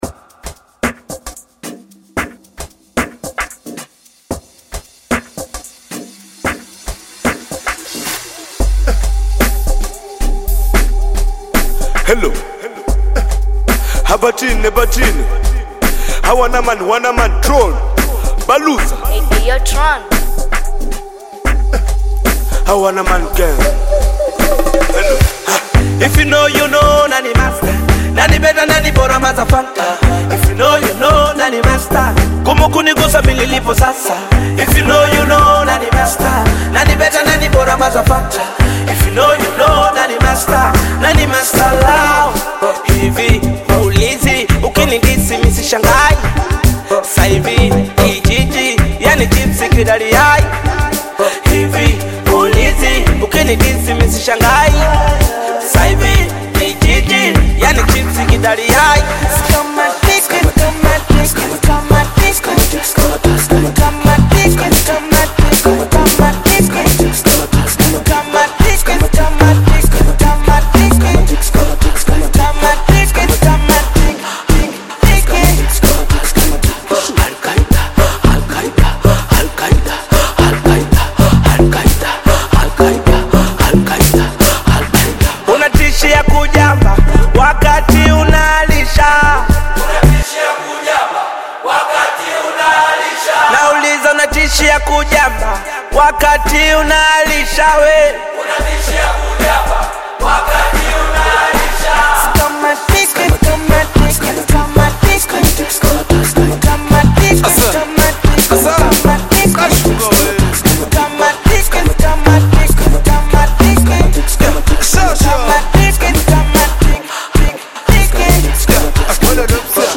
Genre: Amapiano